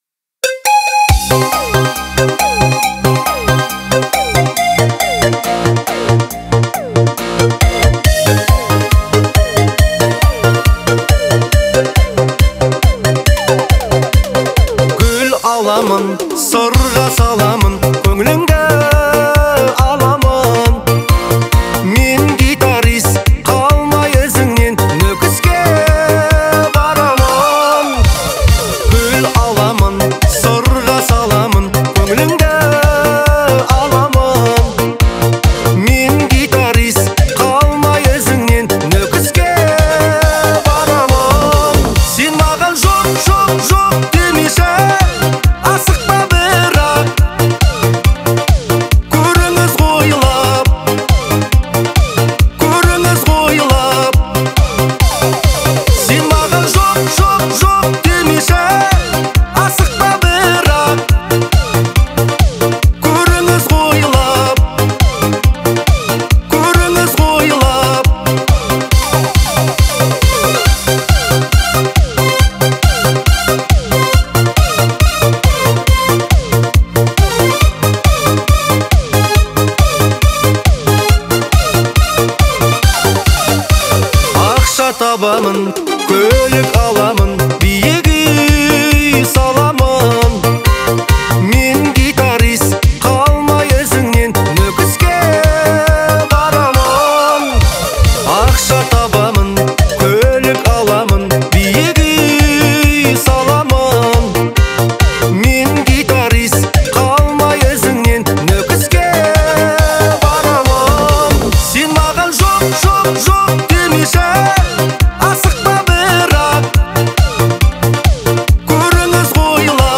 Казахская музыка